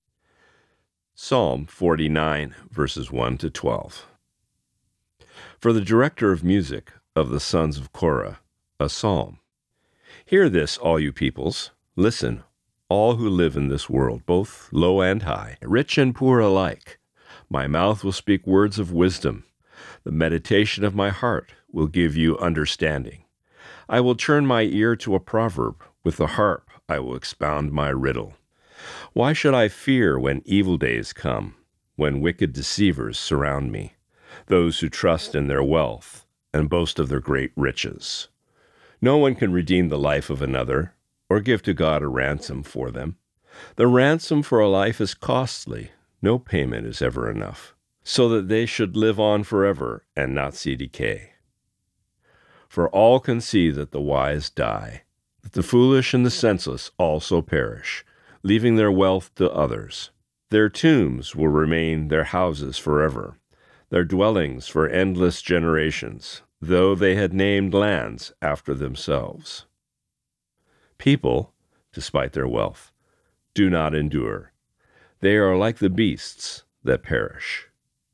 Reading: Psalm 49:1-12